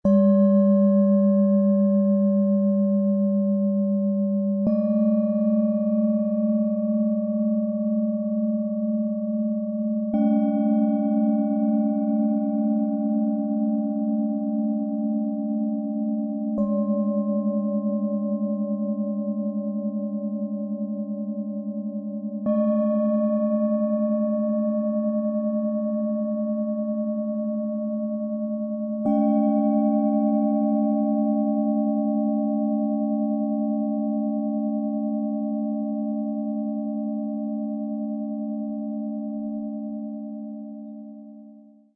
Sanfte Kraft im Fluss - klärend, bewegend, zentrierend - Set aus 3 Klangschalen, Ø 13,1 - 15,2 cm, 1,29 kg
Bewegende Töne lösen, klären und holen dich ins Hier.
Tiefster Ton – tragend, verbindend, lebendig
Mittlerer Ton – sanft, klärend, fließend
Ein klarer, mittlerer Ton, der bei stärkerem Anspielen kraftvoll schwingt.
Hoher Ton – leicht, belebend, aufsteigend
Bihar Schale, Glänzend, 15,2 cm Durchmesser, 7,1 cm Höhe
Bengalen Schale, Glänzend, 13,1 cm Durchmesser, 5,7 cm Höhe